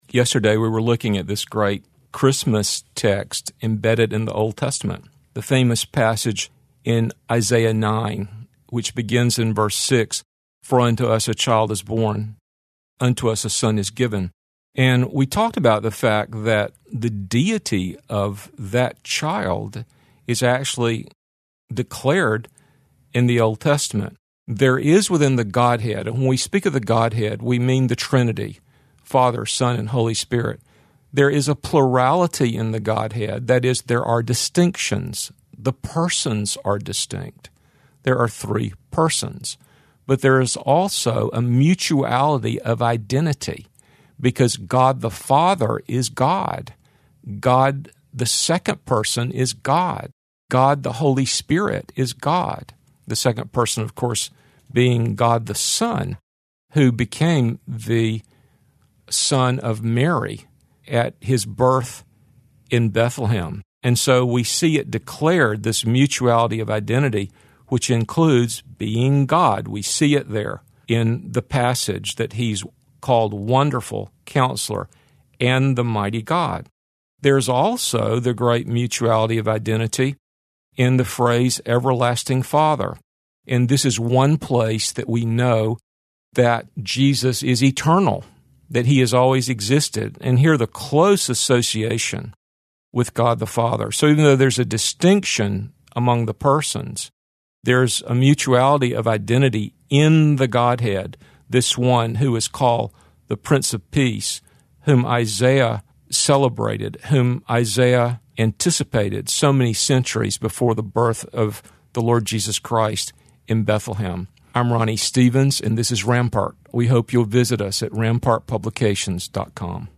two minute radio broadcasts